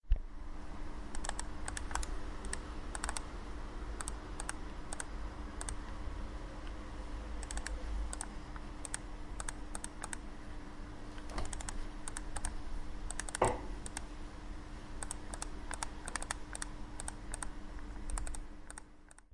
Kn " Click Click
描述：鼠标的声音识别我，因为我在计算机上花了很多时间我听到单击选择或在计算机上导航。用Zoom H1录音机录制。